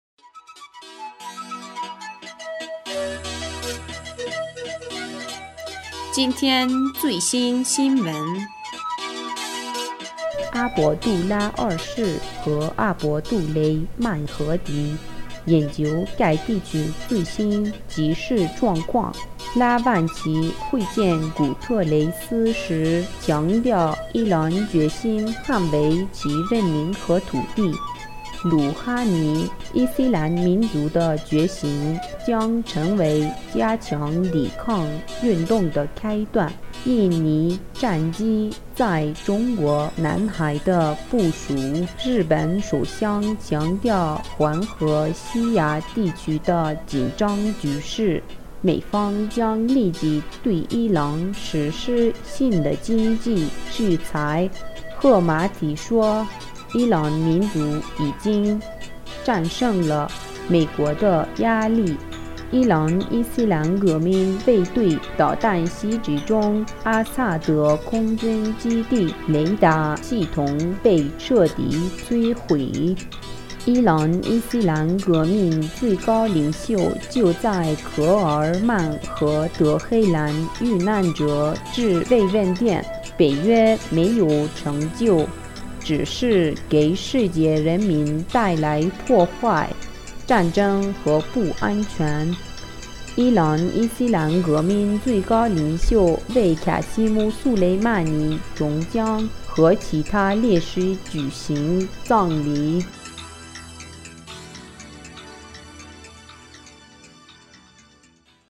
2020年1月9日 新闻